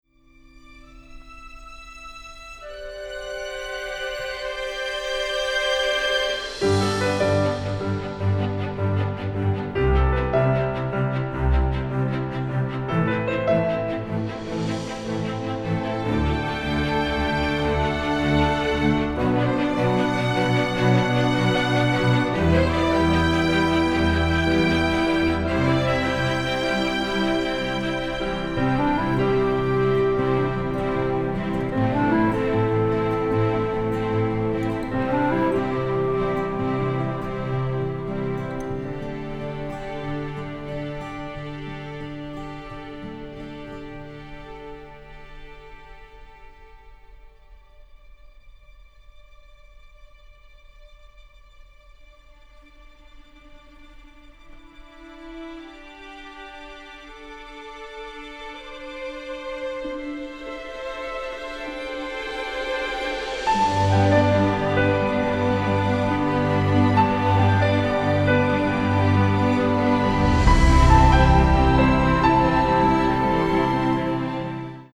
encompassing tenderness, sadness and nostalgia